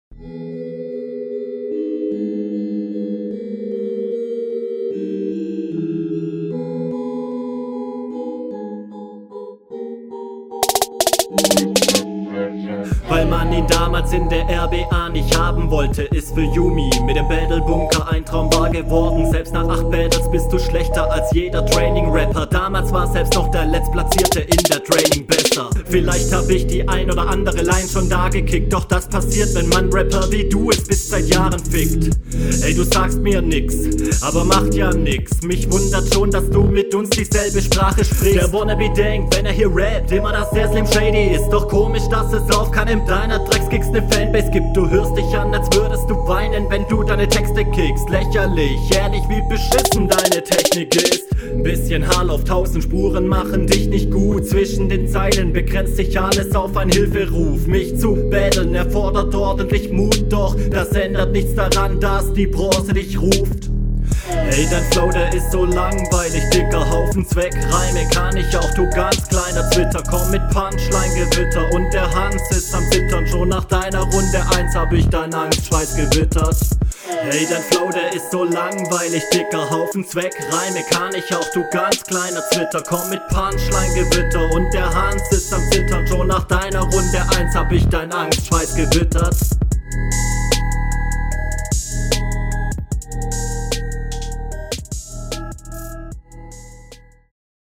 Cooler Flow, Technik dissen ist immer eh, sonst coole Konter.